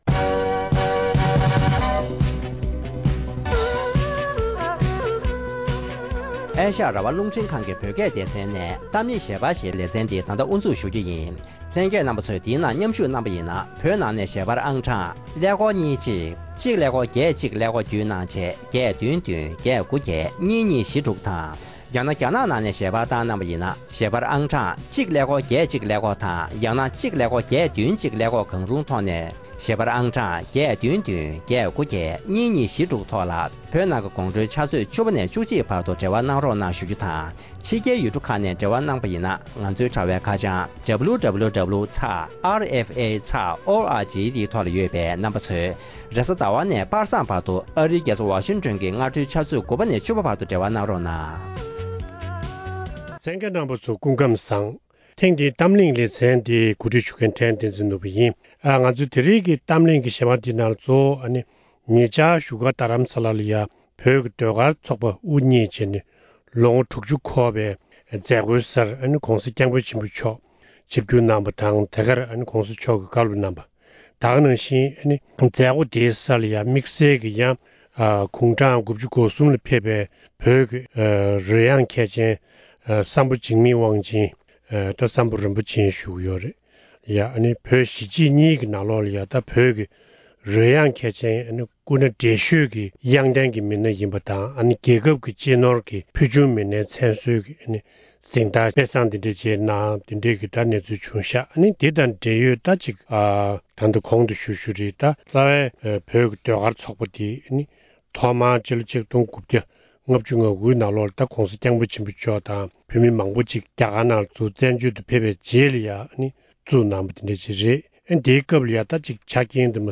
༧གོང་ས་༧སྐྱབས་མགོན་ཆེན་པོ་མཆོག་གིས་བོད་ཀྱི་ཟློས་གར་ཚོགས་པ་བཙུགས་ནས། ལོ་༦༠་འཁོར་བའི་དུས་དྲན་ཉིན་བཀའ་སློབ་གསུང་བཤད་གནང་ཡོད་པ།